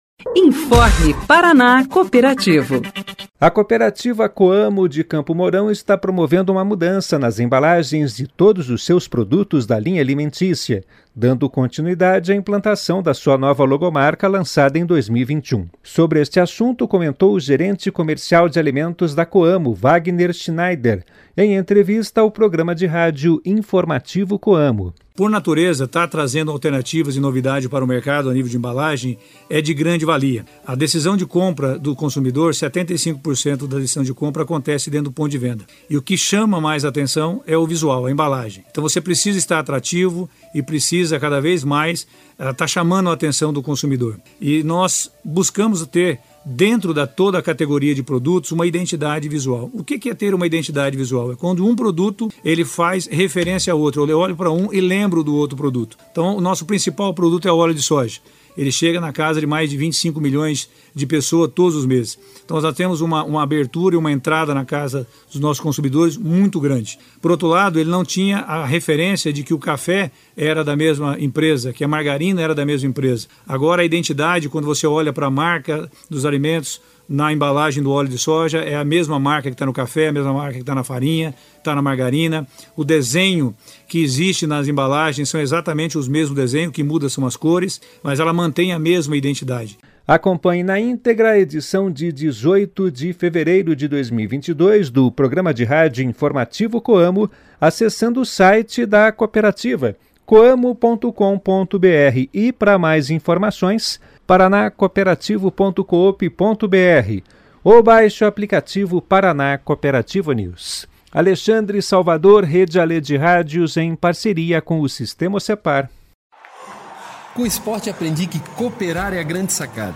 em entrevista ao programa de rádio "Informativo Coamo".